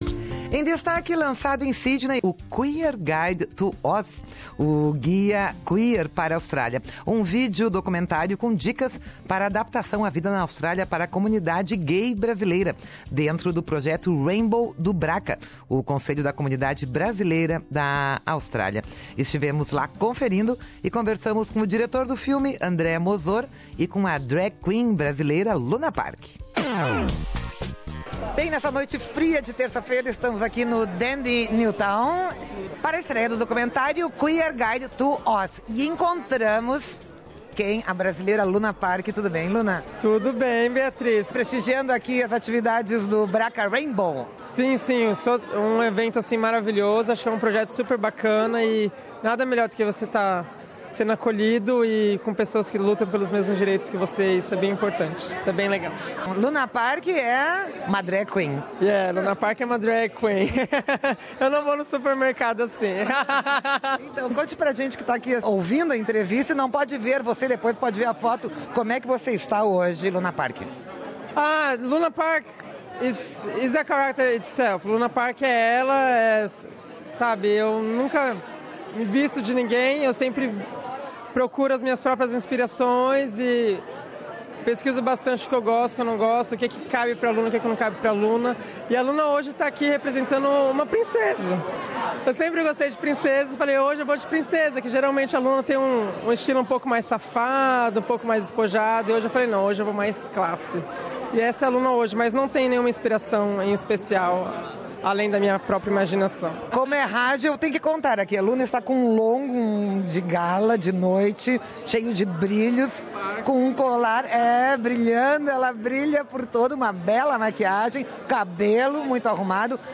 na noite de lançamento do vídeo